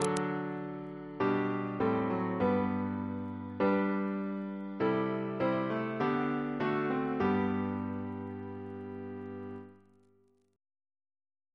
Single chant in G Composer: Henry Purcell (1659-1695) Reference psalters: ACP: 124; PP/SNCB: 170